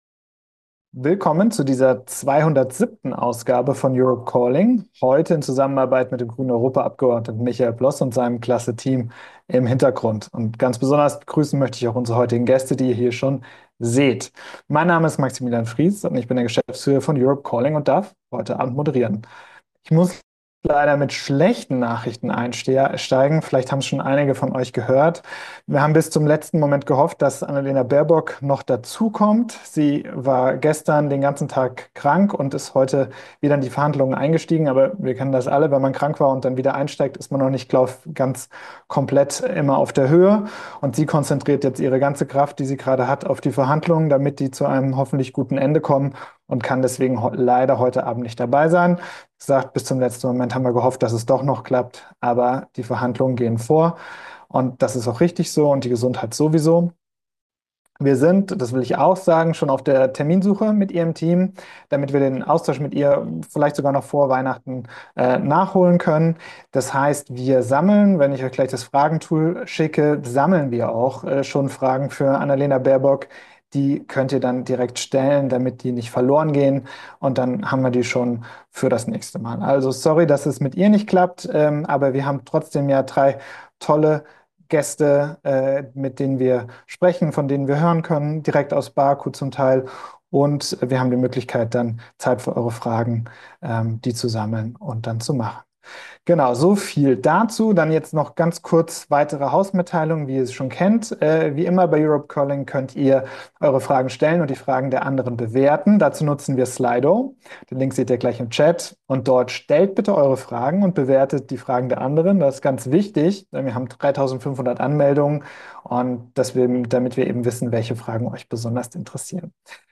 Europe Calling #207 Live von der Weltklimakonferenz ~ Europe Calling Podcast
Aufzeichnung der 207. Ausgabe von Europe Calling